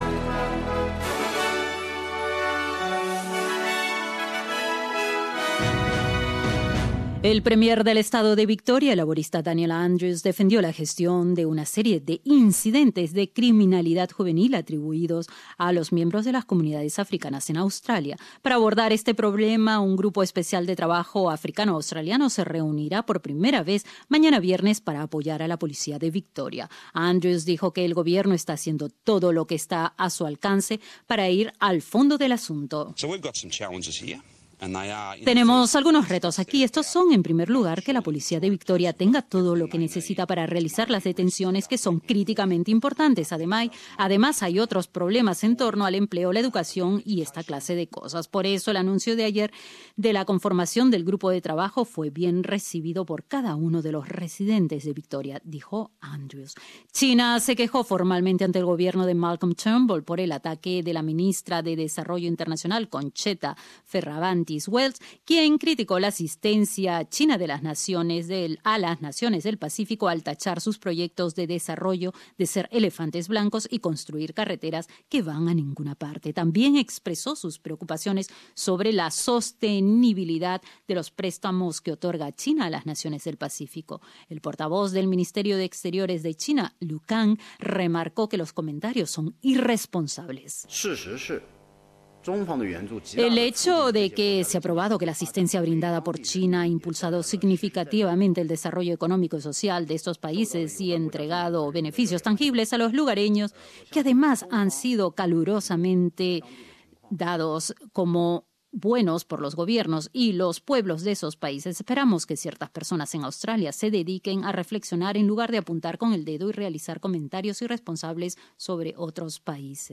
Noticiero del 11 de enero de 2018
Escucha el noticiero del 11 de enero de 2018